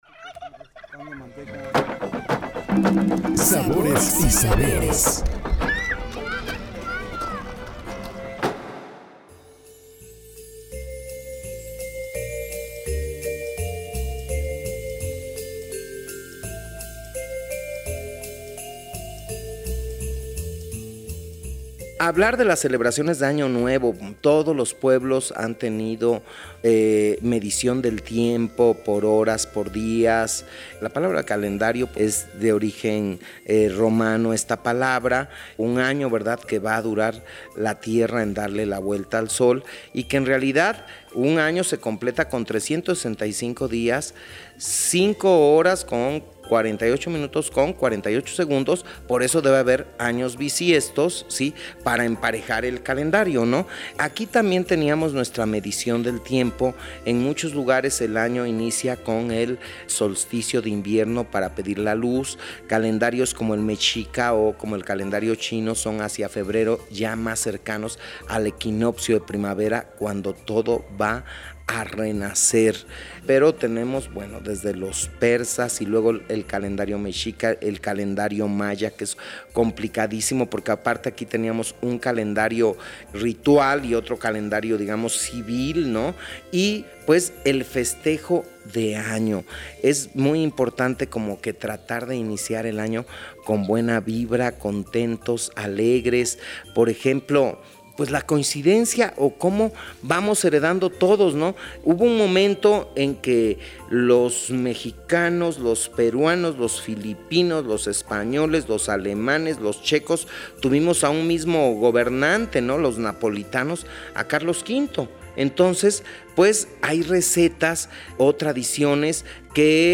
RMI revive la entrevista